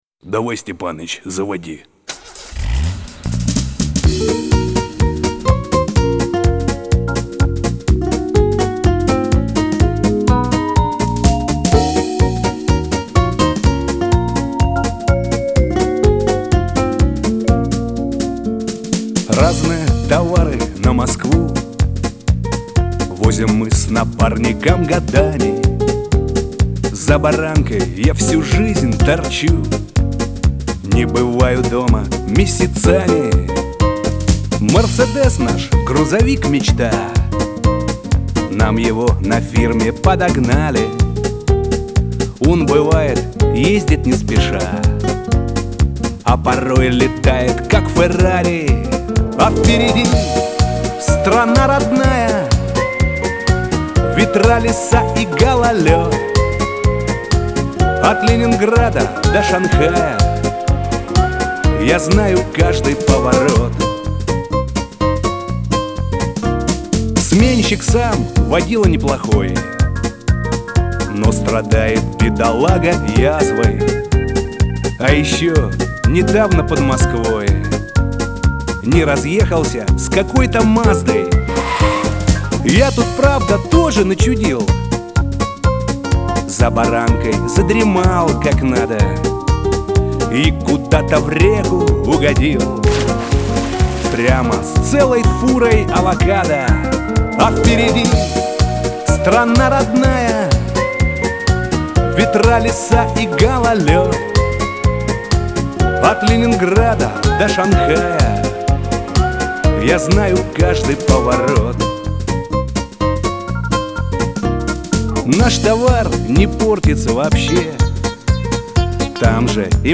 Всі мінусовки жанру Shanson
Плюсовий запис